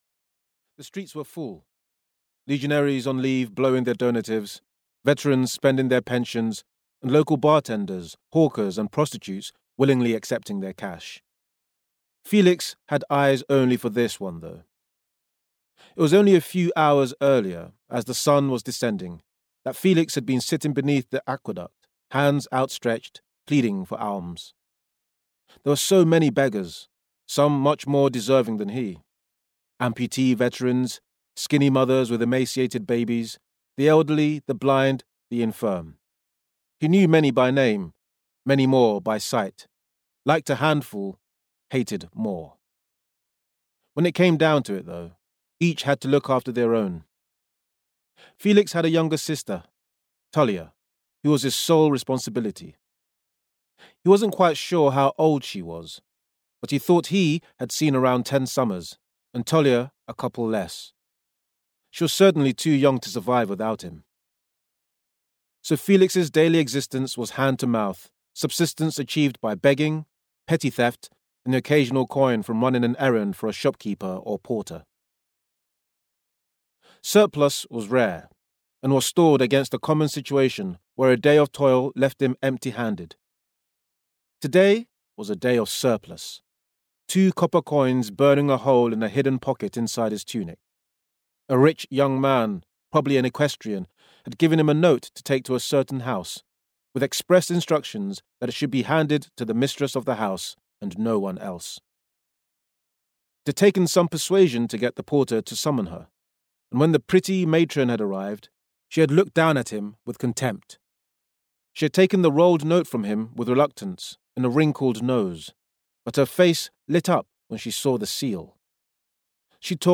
Carbo and the Thief (EN) audiokniha
Ukázka z knihy